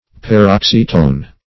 Search Result for " paroxytone" : Wordnet 3.0 NOUN (1) 1. word having stress or acute accent on the next to last syllable ; The Collaborative International Dictionary of English v.0.48: Paroxytone \Par*ox"y*tone\, n. [Gr.
paroxytone.mp3